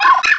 pokeemerald / sound / direct_sound_samples / cries / riolu.aif